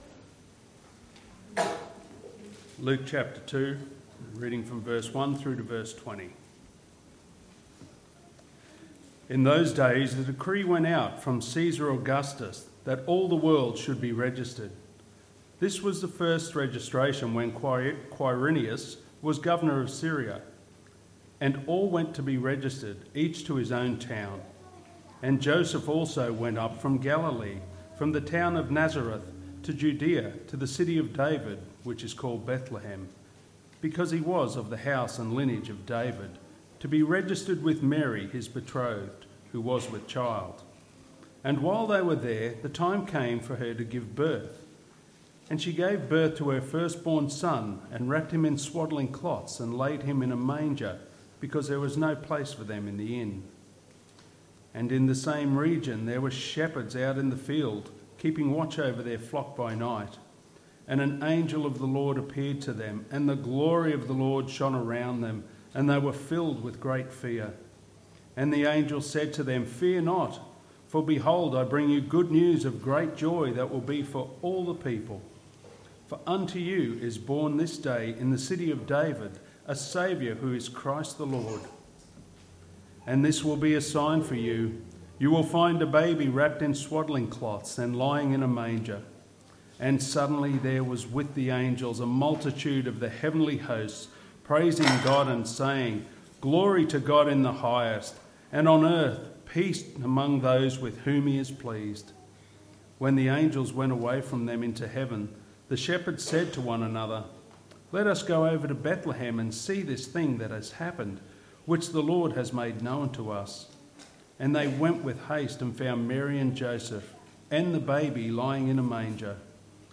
Christmas Service 2023